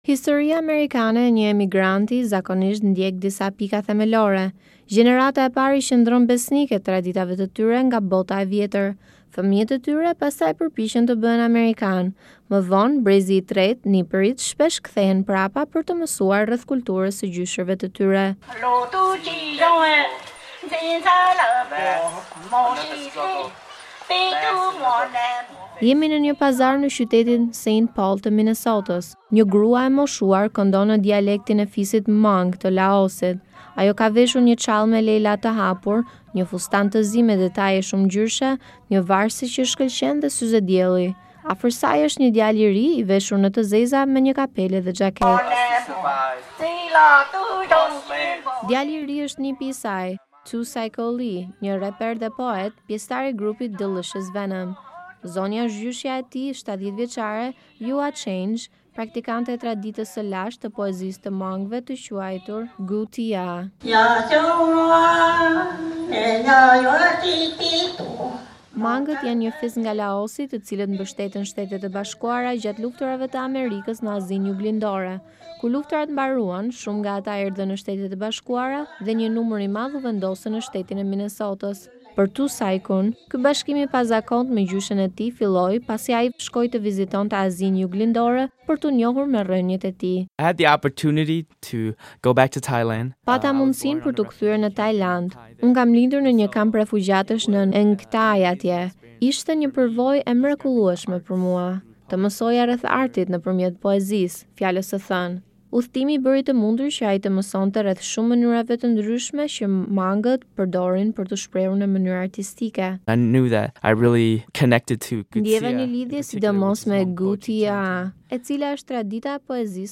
Dueti nip dhe gjyshe bashkojnë traditën e lashtë laosiane të poezisë “kwv txhiaj” me hip-hopin